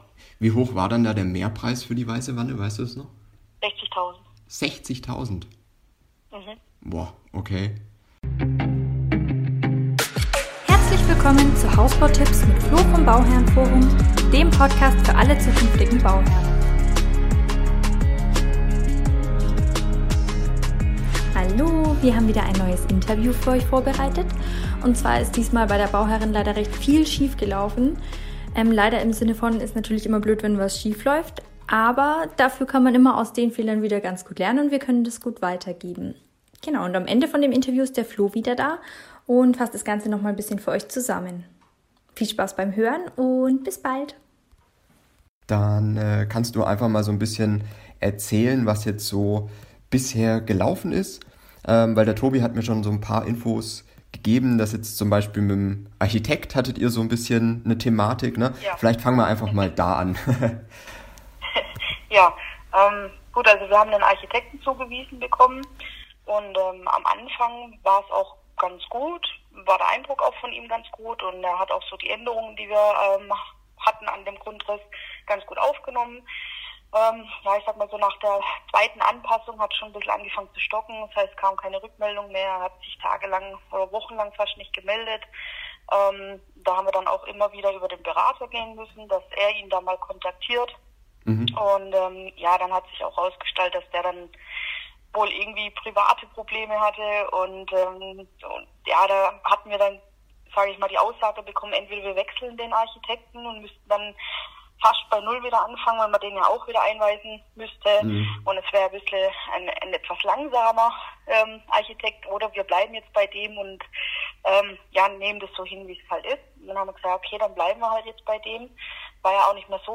Heute gibt`s die nächste Interview Folge